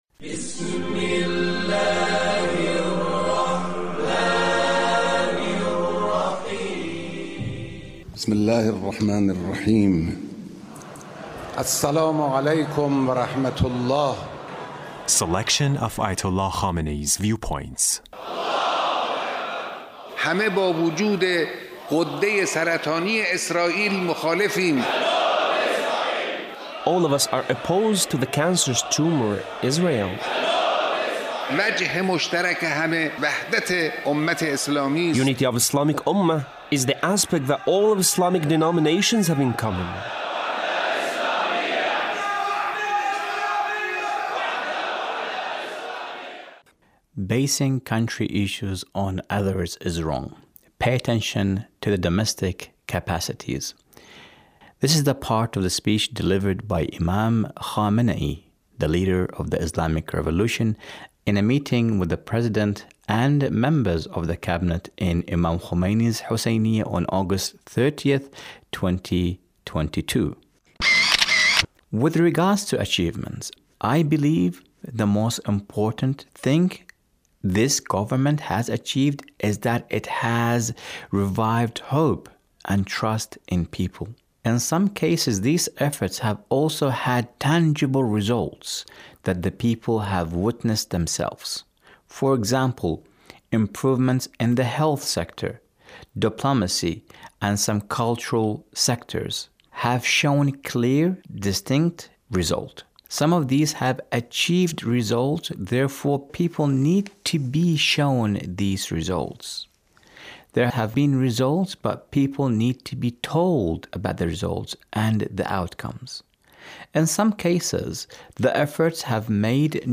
Leader's Speech